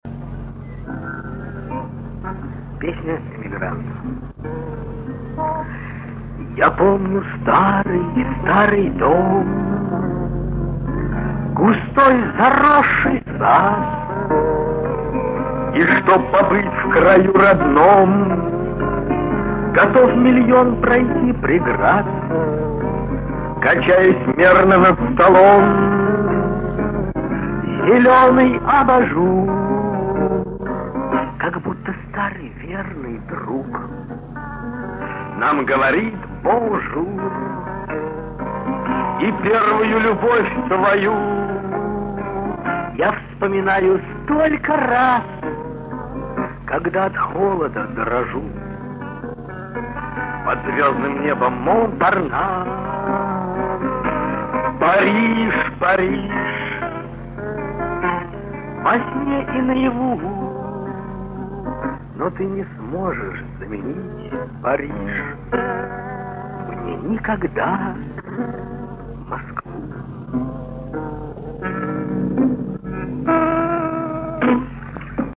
Домашняя Запись